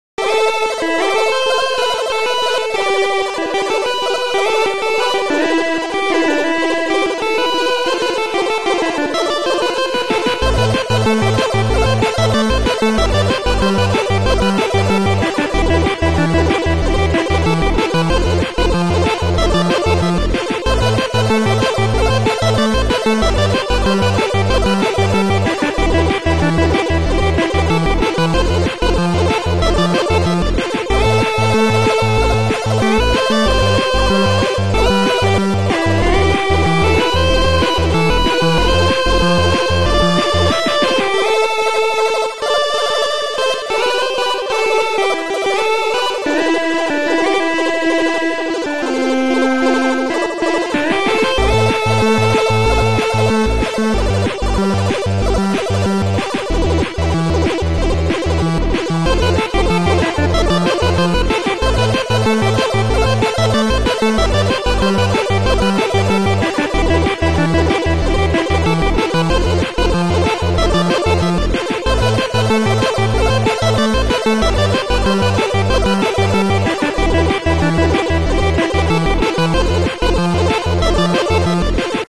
Keygen Music
chiptunes